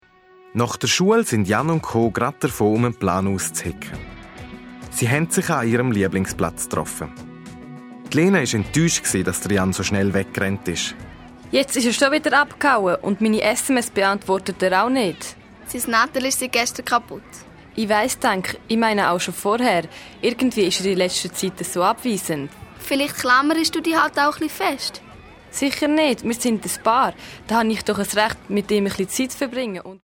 Das spannende Detektivhörspiel zum Thema Jugendgewalt greift die aktuelle Problematik auf, der viele Schülerinnen und Schüler ausgesetzt sind: Das Verständnis, dass das eigene Ansehen nur durch Markenkleider und Zurückschlagen gewahrt werden könne.